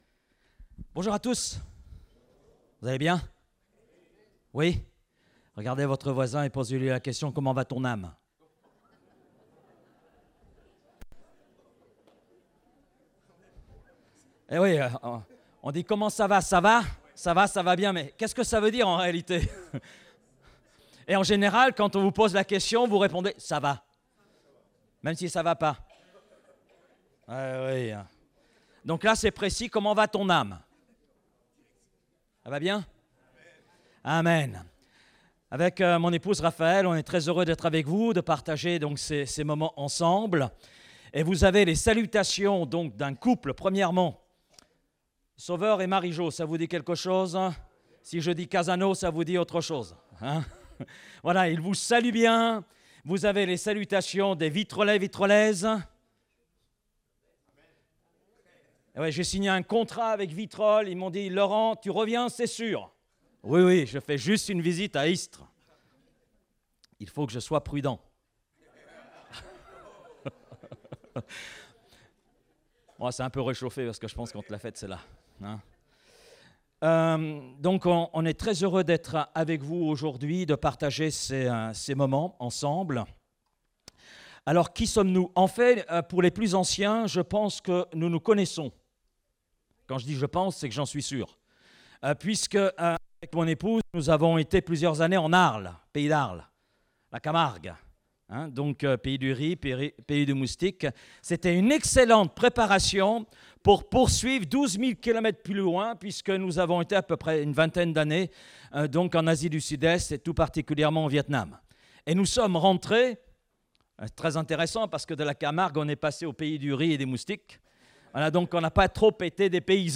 Date : 21 avril 2024 (Culte Dominical)